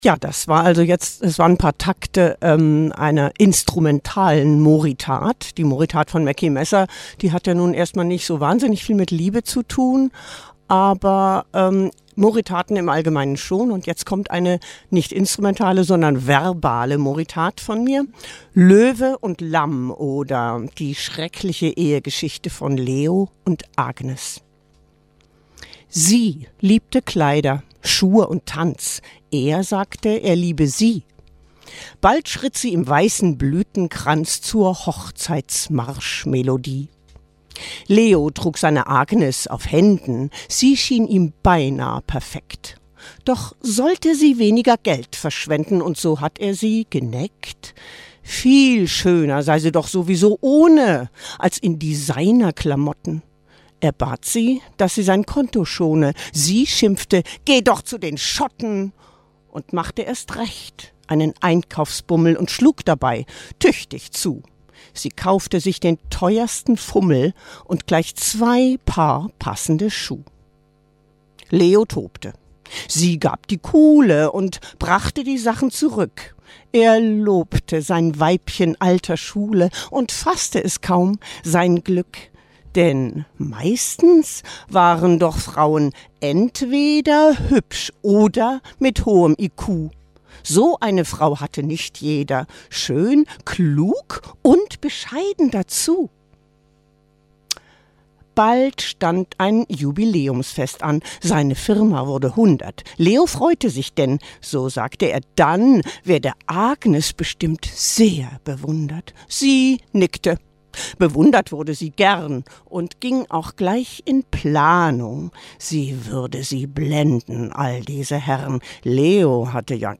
Ich las meine Moritat "Löwe und Lamm - oder die schreckliche Ehegeschichte von Leo und Agnes", nachzuhören